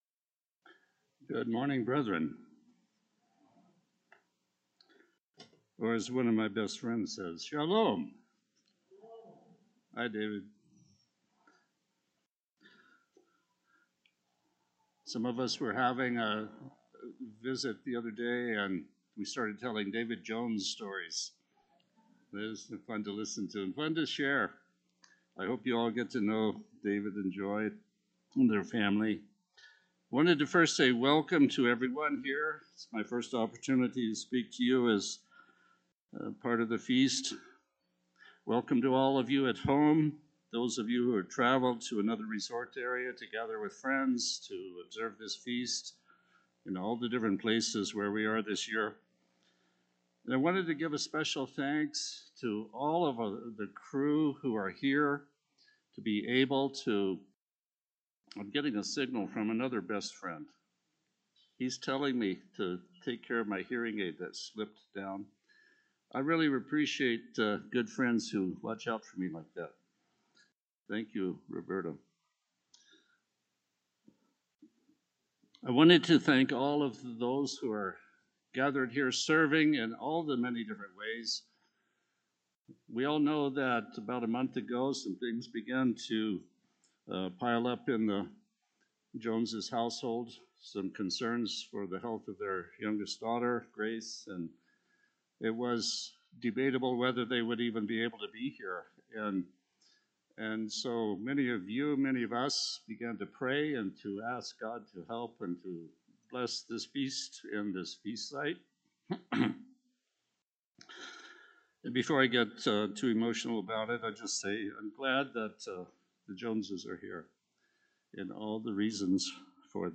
A split sermon given during the Feast of Tabernacles in Glacier Country, Montana, 2020.
This sermon was given at the Glacier Country, Montana 2020 Feast site.